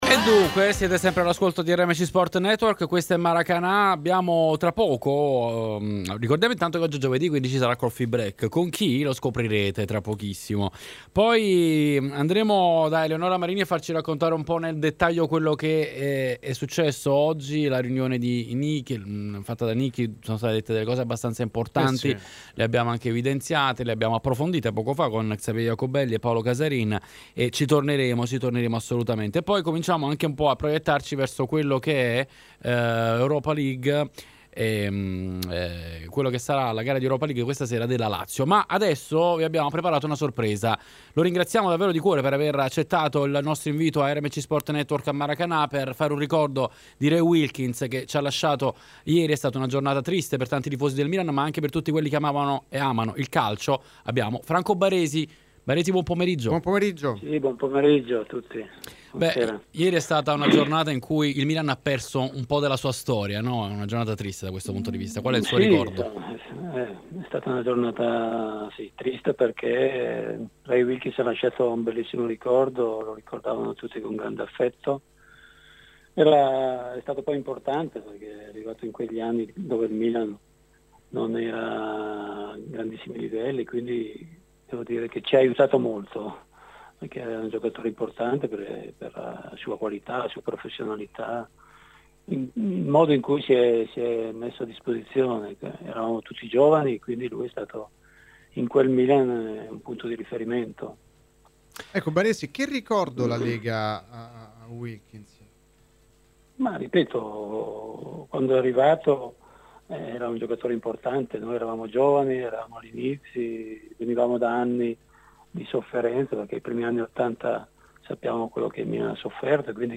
Franco Baresi, in diretta su RMC Sport, ha ricordato Raymond Colin Wilkins, ex suo compagno ai tempi del Milan scomparso nella giornata di ieri:
Franco Baresi ex calciatore intervistato